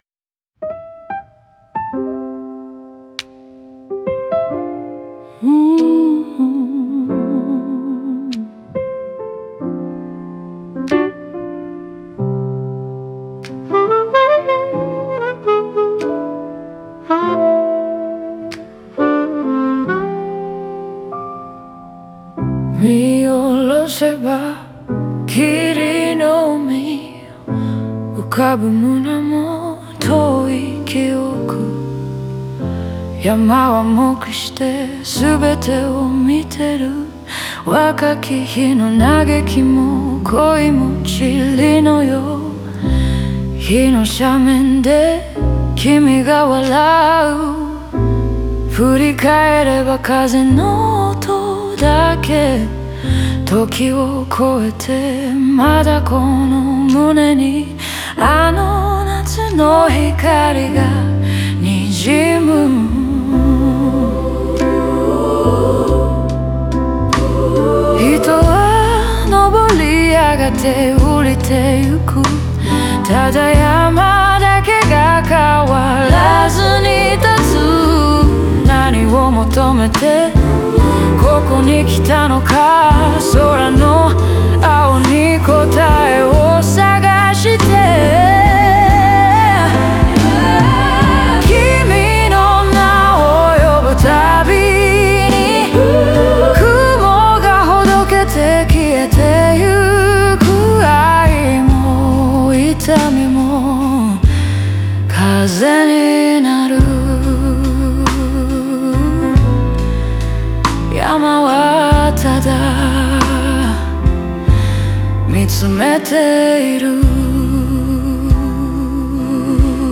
声は遠く、風とひとつになるように――